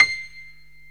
PIANO 0007.wav